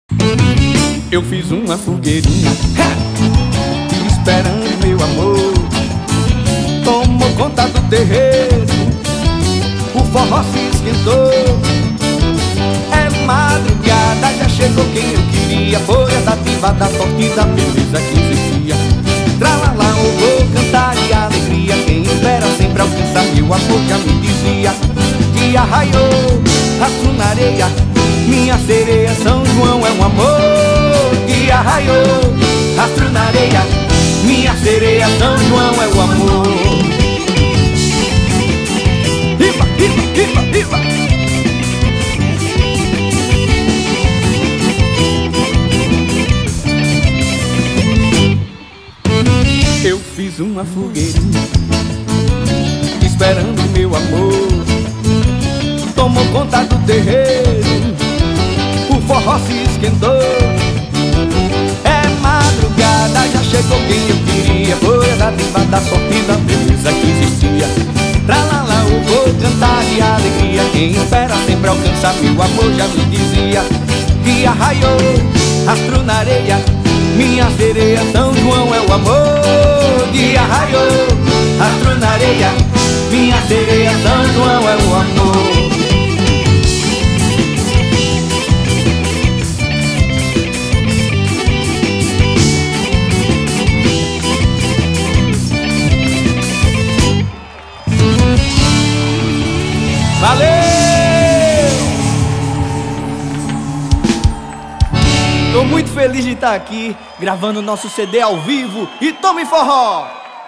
Gravado ao vivo no São João de Serra Negra em Bezerros - PE.